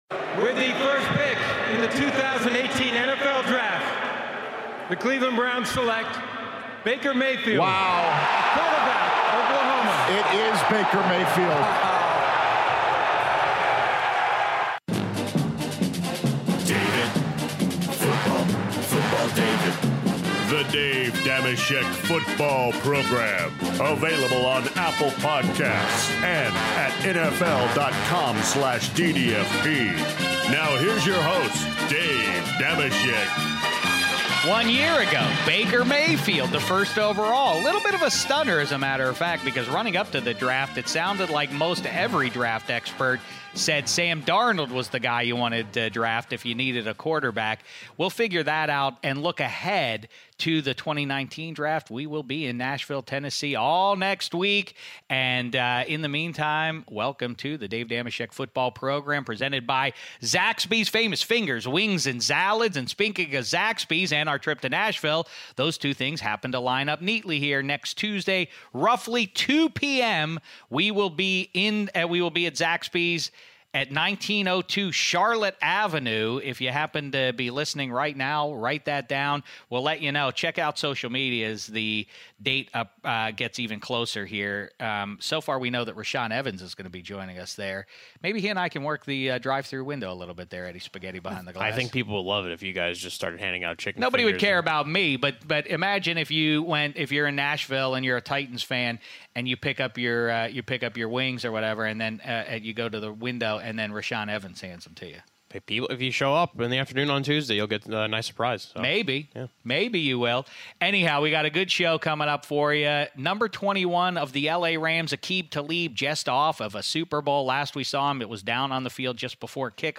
Dave Dameshek is joined by two big guests this episode!
Then, Los Angeles Rams cornerback Aqib Talib sits down in Studio 66 with Shek to kibitz about SB LIII, Sean McVay as a head coach and the new 'Roby rule' (33:43).